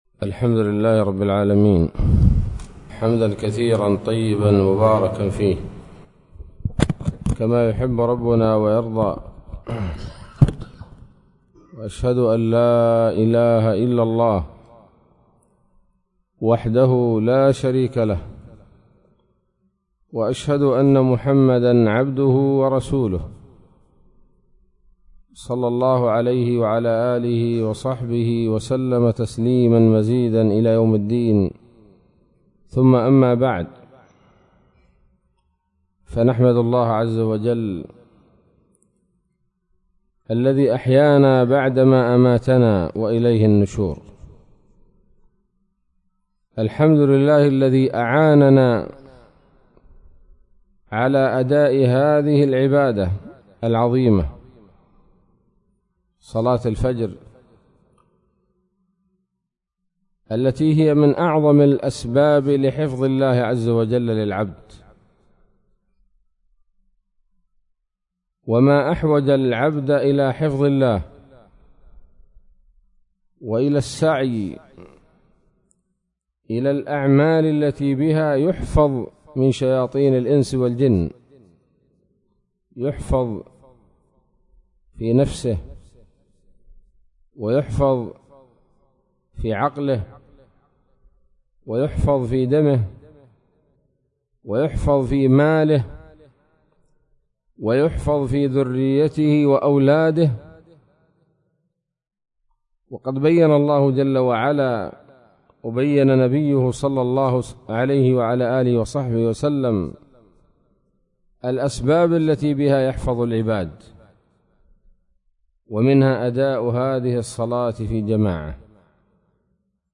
كلمة قيمة بعنوان: (( من صلى الفجر في جماعة فهو في ذمة الله )) فجر السبت 15 ربيع الآخر 1443هـ، بمنطقة المخا